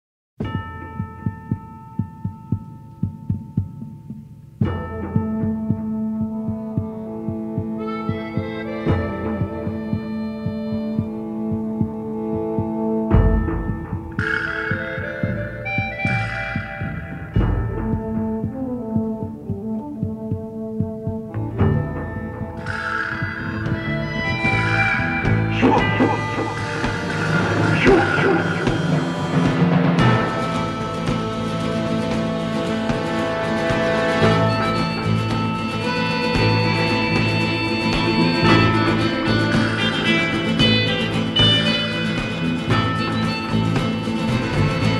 climactic spaghetti western spoof